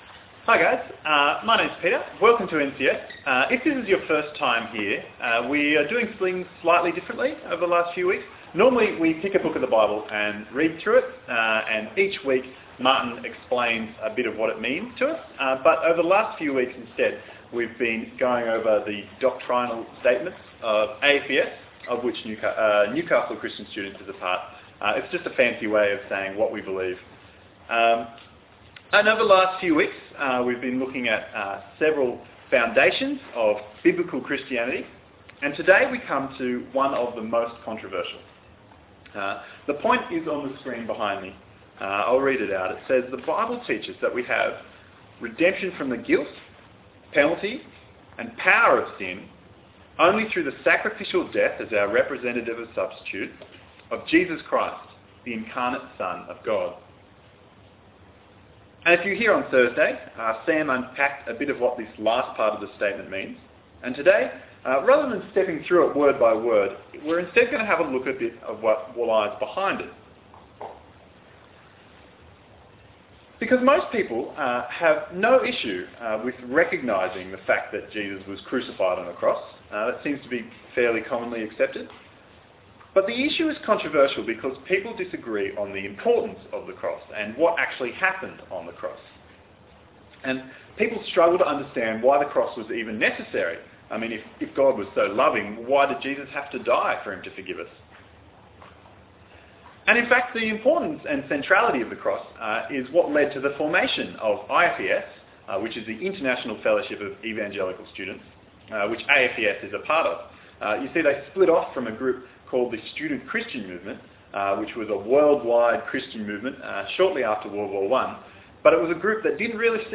Passage: Romans 3:10-26 Talk Type: Bible Talk « Incarnation